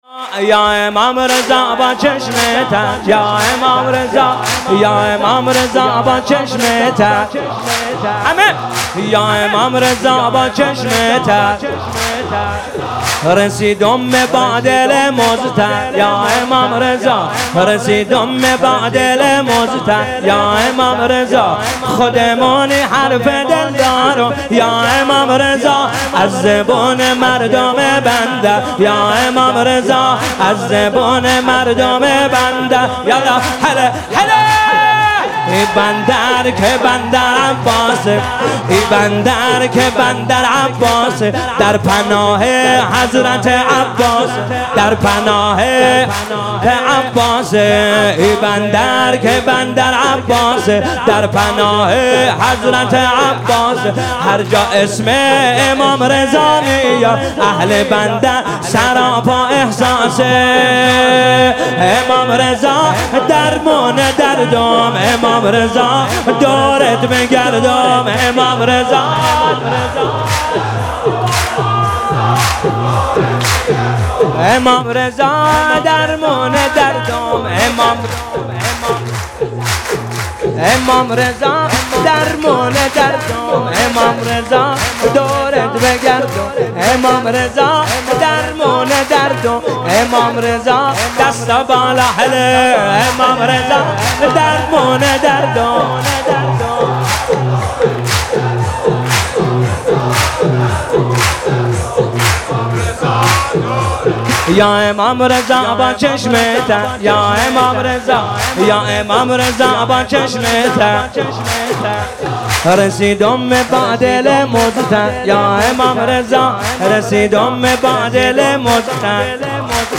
مولودی جدید بندرعباس